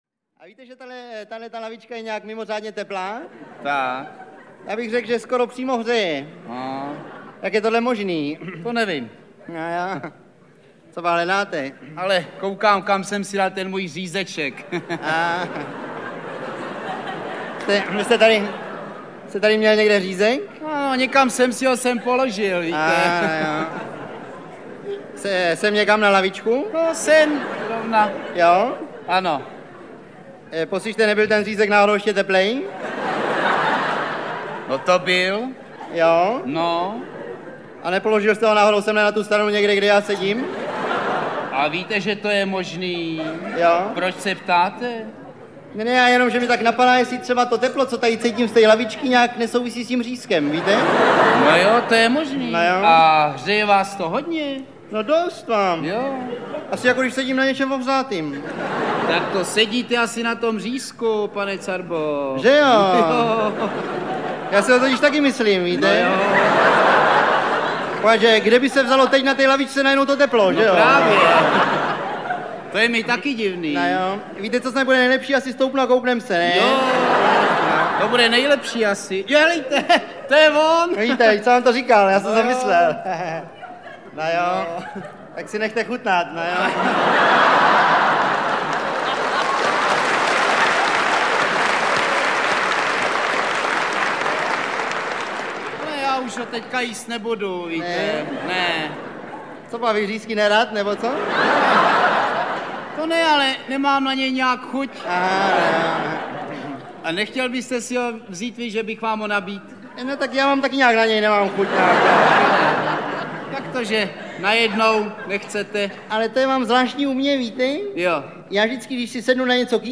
Šprechty v plném počtu audiokniha
Ukázka z knihy